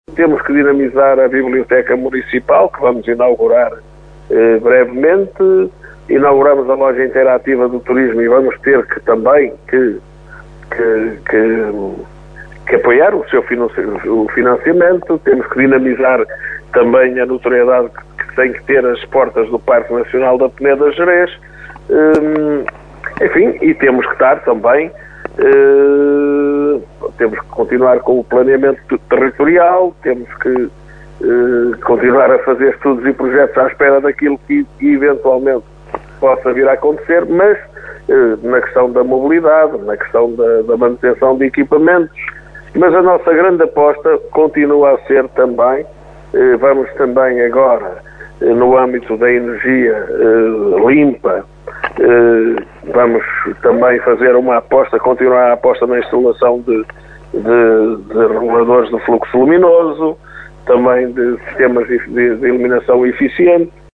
Vassalo Abreu adianta algumas das prioridades para o próximo ano.